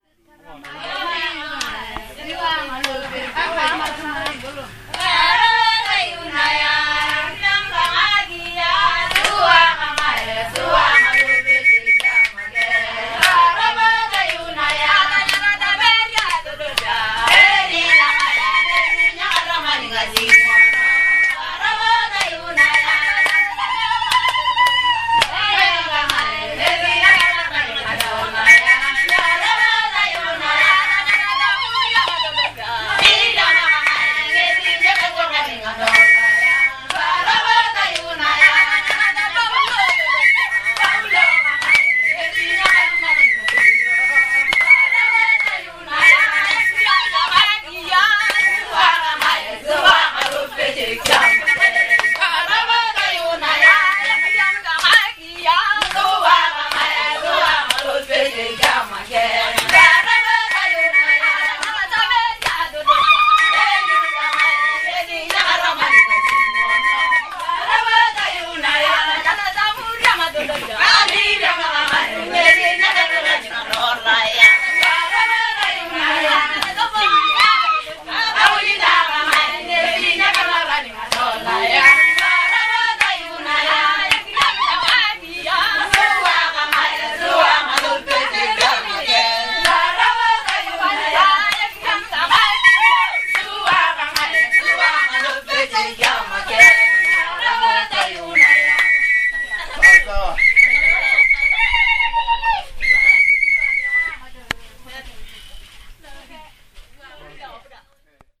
Fishing women singing near Lake Turkana, Kenya
A group of women celebrate the arrival of some visitors.